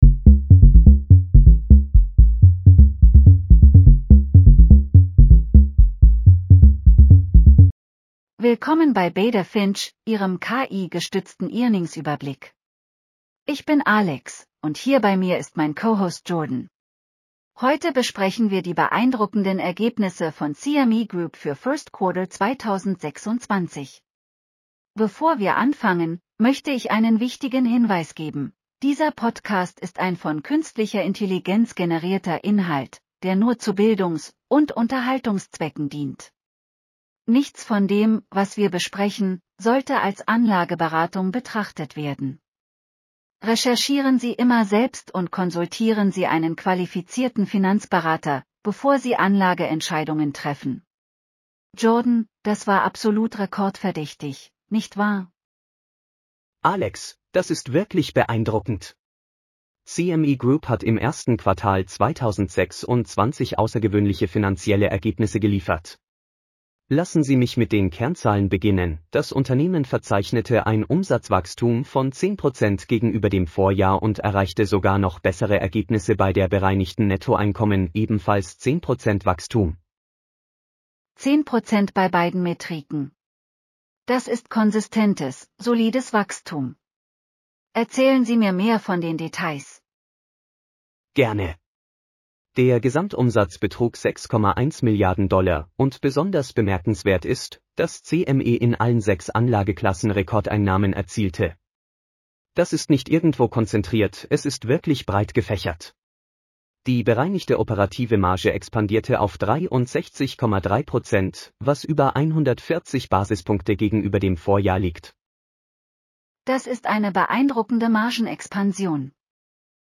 Willkommen bei Beta Finch, Ihrem KI-gestützten Earnings-Überblick.